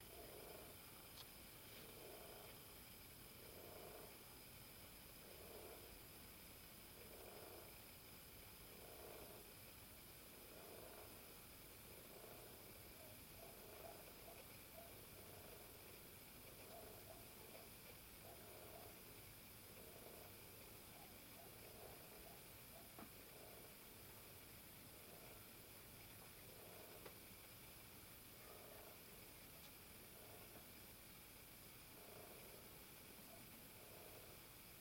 Каждый вечер одно и тоже, как вы думаете кому могут принадлежать эти звуки ?
В доме.
Похоже на какой-то электрический механизм с периодическими колебаниями, возможно вращением.
Короче не ломайте голову, это осы в стене, по данным википедии когда они начинают заниматься любовью, рабочие осы своими крыльями охлаждают матку, отсюда и звук такой .
слишком какая-то строгая периодичность у звука... математически выверенная.